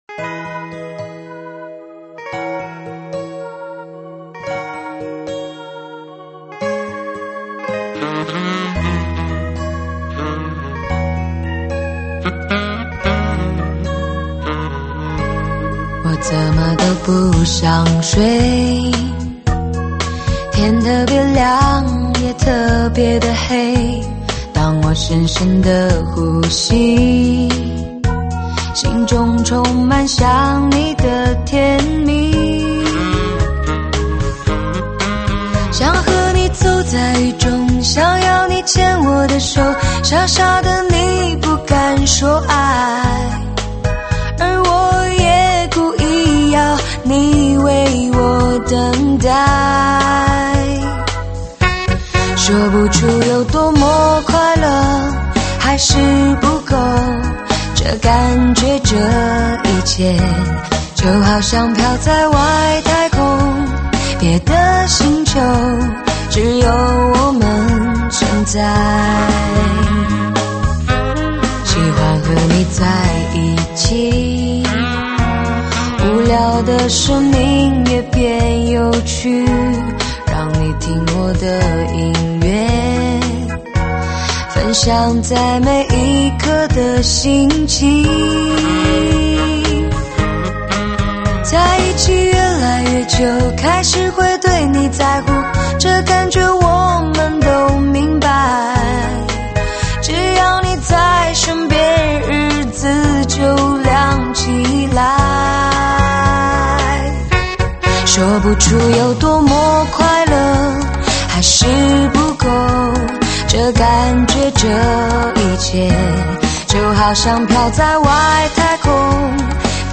伤感情歌